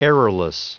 Prononciation du mot errorless en anglais (fichier audio)
Prononciation du mot : errorless